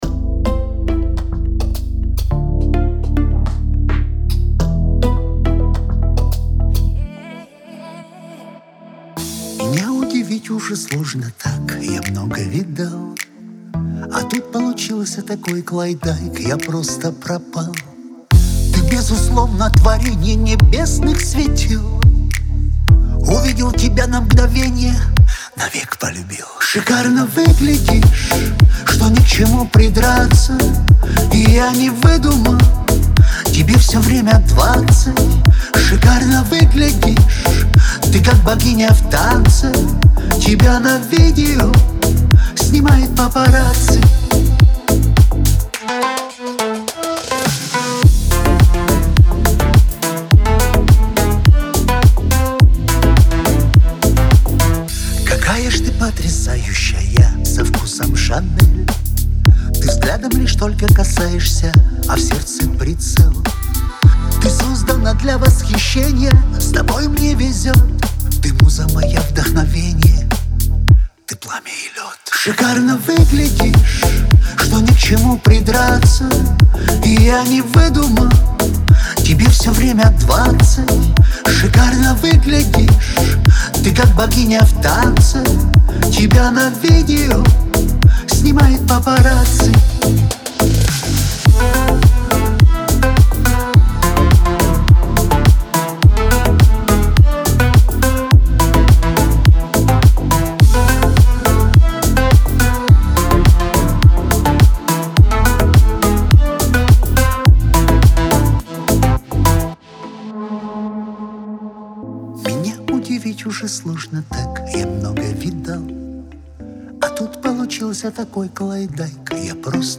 танцы
эстрада , диско , pop